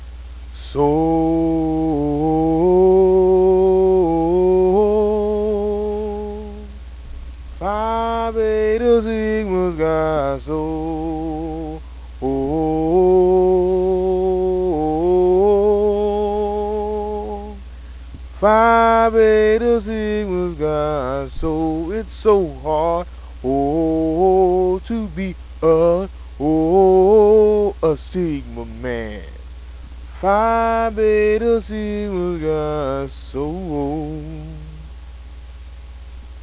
Sigma Chapter Chants